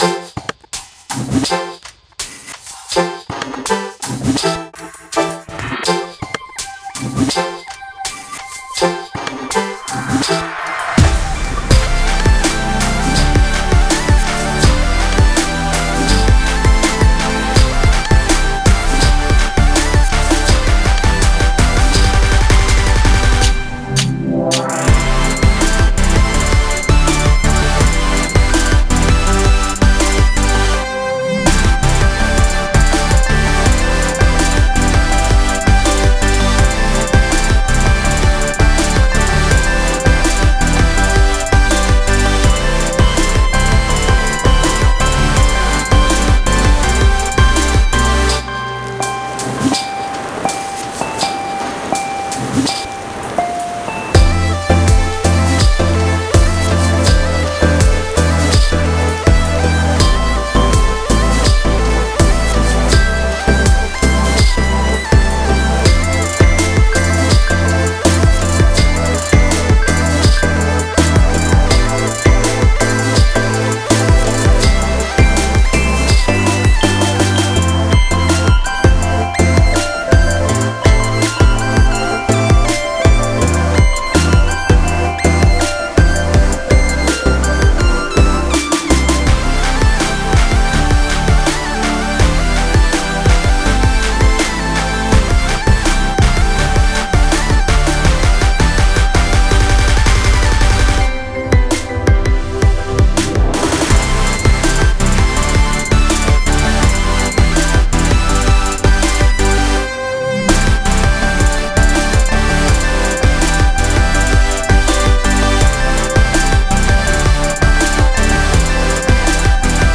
ハルジオンは僕がEDMという単語をしり、kawaii future bass とやらを知ることになり、こんな感じの曲作ってみたい！
03_harujion_offvocal.wav